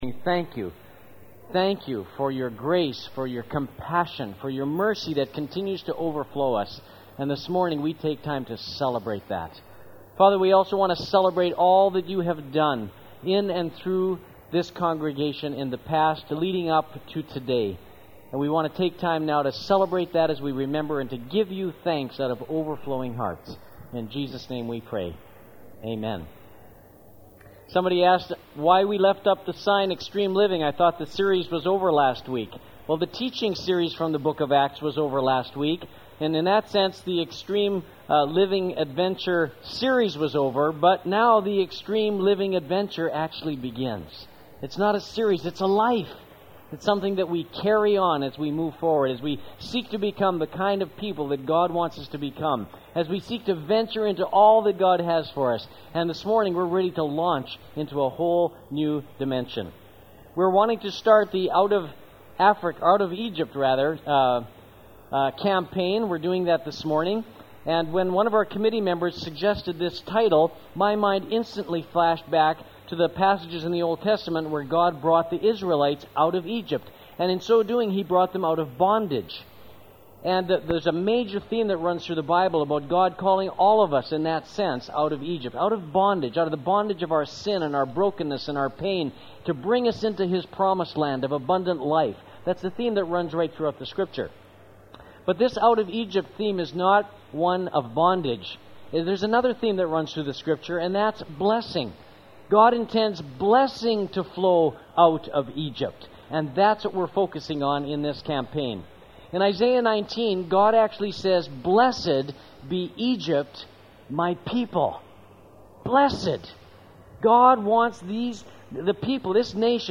*Note: The exact date for this sermon is unknown.